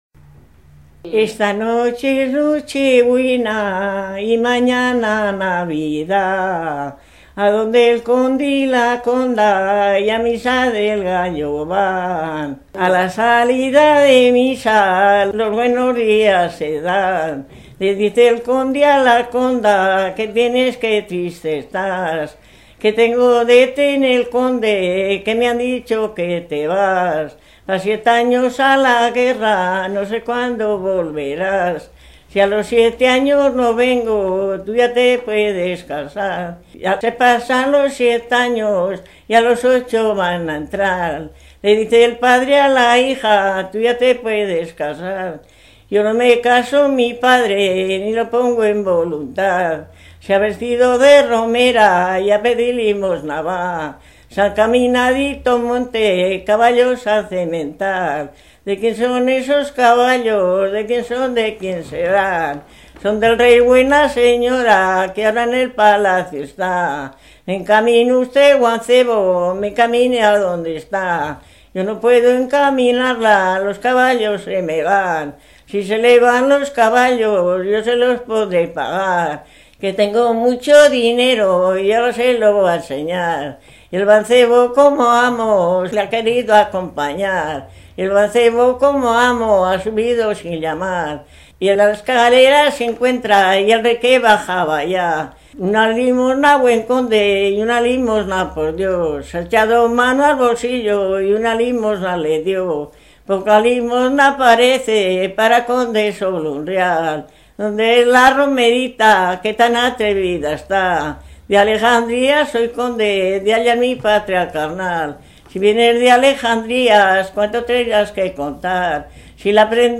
Clasificación: Romancero
Lugar y fecha de grabación: Logroño, 25 de febrero de 2000
Esta versión de La boda estorbada se cantaba en Ribafrecha al son de las zambombas navideñas y rondando las calles del pueblo.
Se cantaba pidiendo los aguinaldos, un solista entonaba una estrofa y el coro de pedigüeños la repetían. En la grabación no se nos ocurrió incorporar una zambomba pero en el ritmo del romance se nota la cadencia propia de este instrumento.